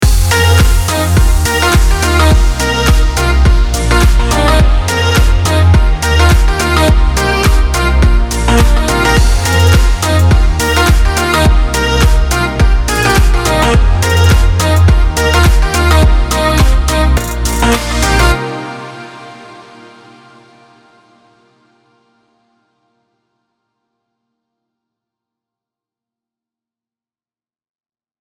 sommerlichen Tune